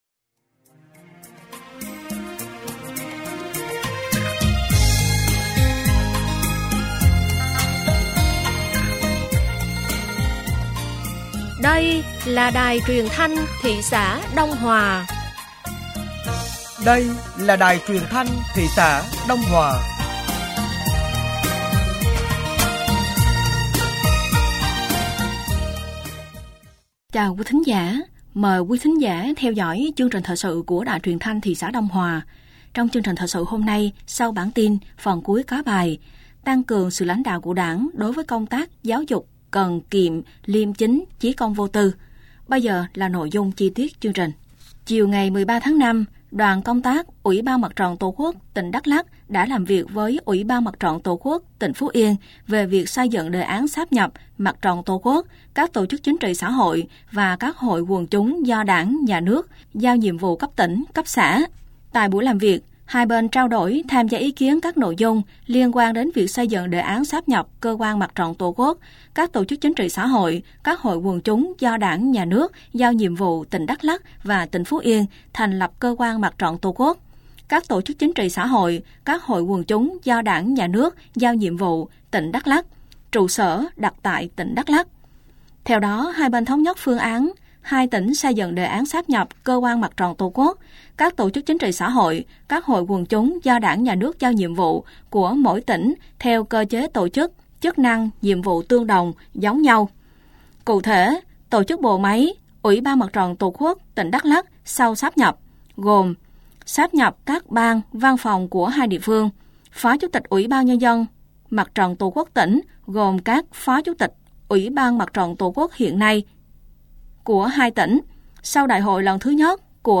Thời sự tối ngày 14 và sáng ngày 15 tháng 5 năm 2025